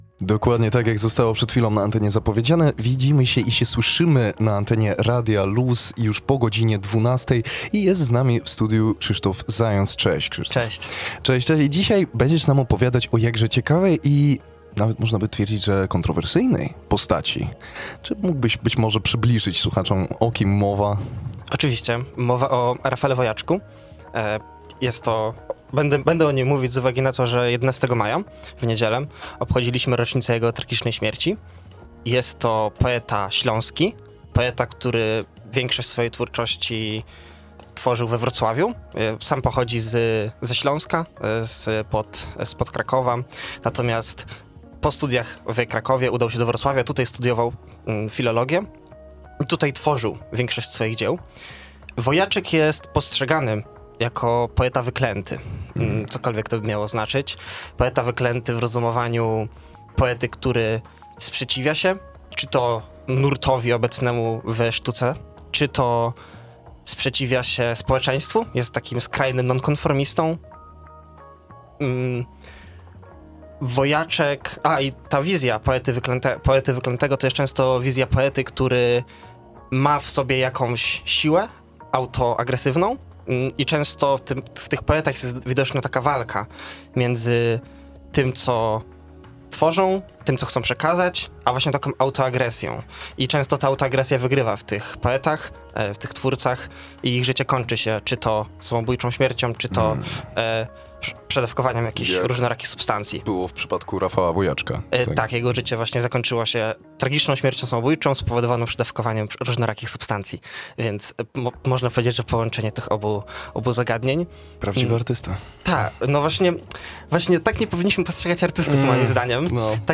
Podczas audycji Pełna Kultura przybliżyliśmy jego postać.